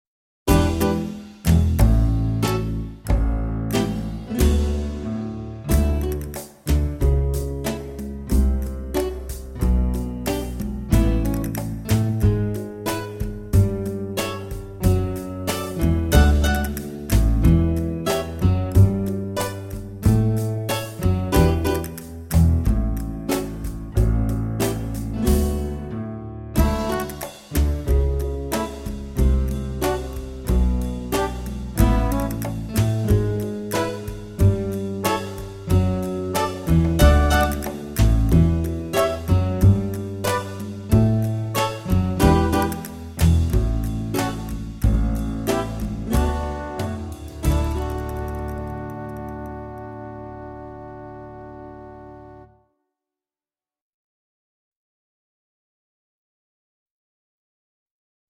VS Spanish Steps (backing track)